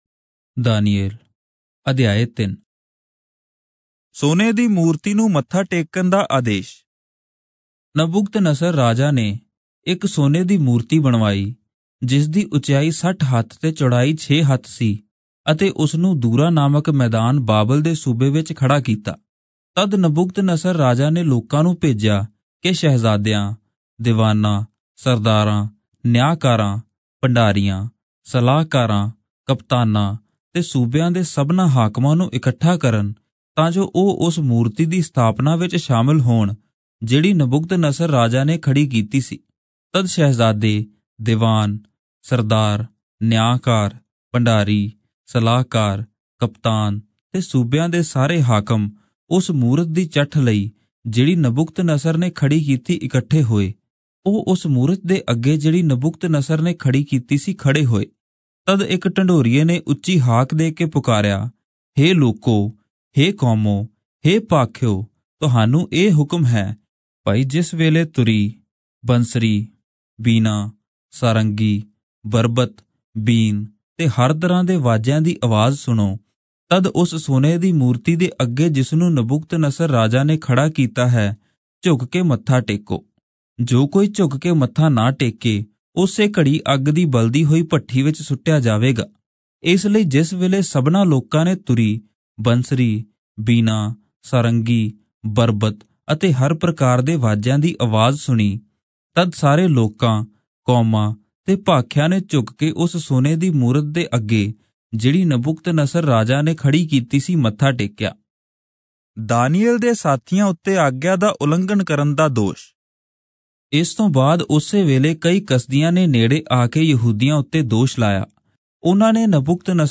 Punjabi Audio Bible - Daniel 2 in Irvpa bible version